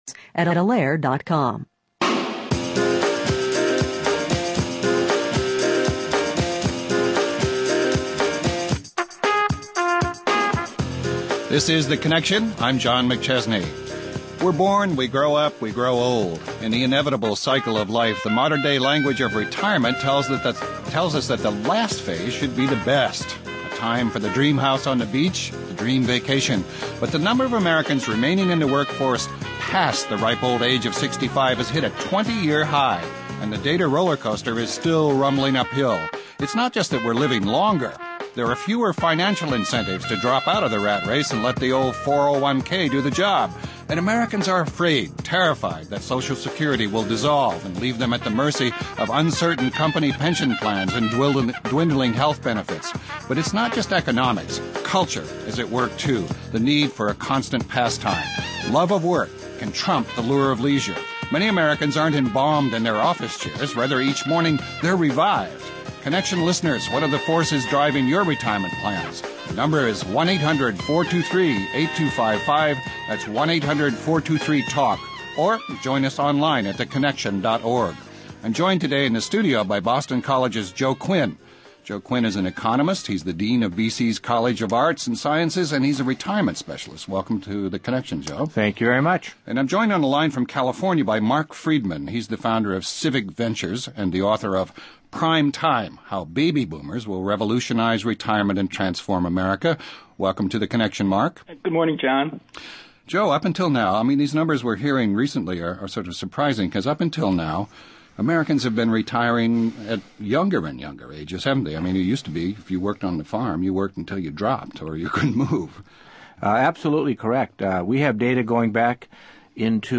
(Hosted by Christopher Lydon)